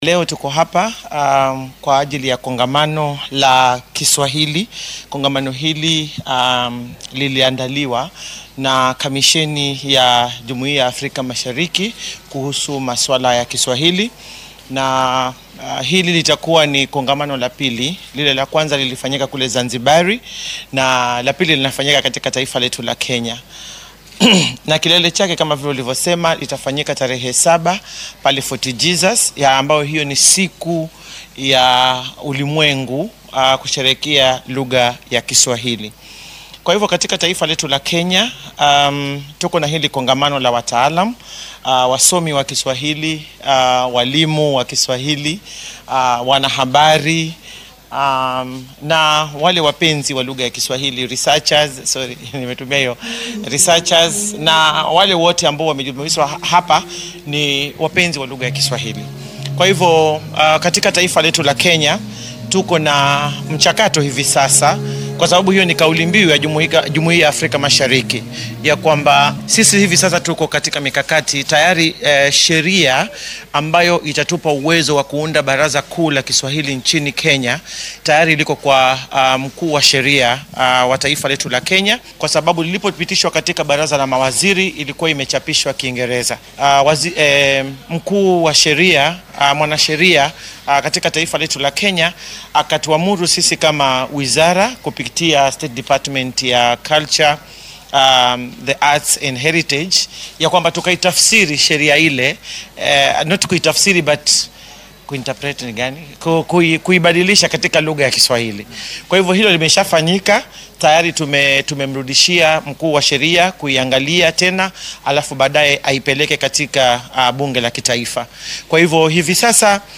Wasiiradda wasaaradda jinsiyadda , dhaqanka , farshaxanka iyo hiddaha ee dalka Aisha Jumwa oo marti sharaf ka ah shirka ayaa warbaahinta faahfaahin ka siisay.